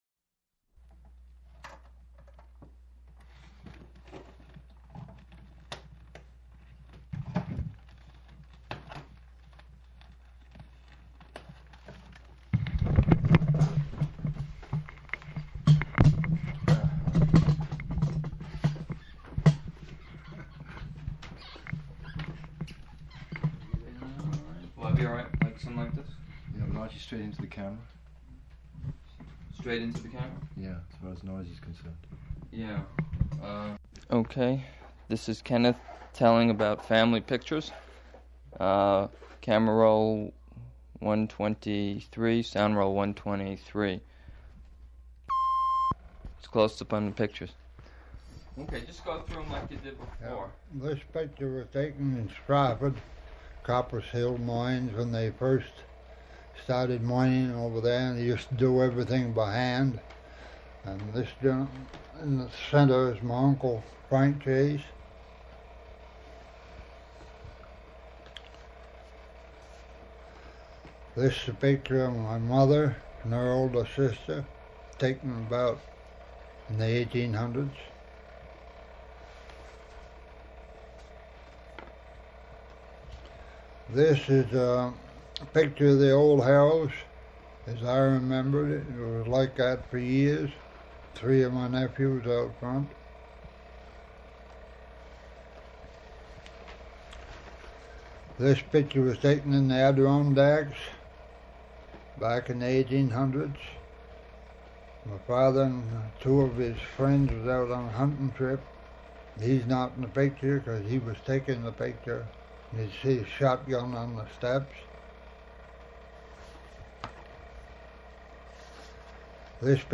Format 1 sound tape reel (Scotch 3M 208 polyester) : analog ; 7 1/2 ips, full track, mono.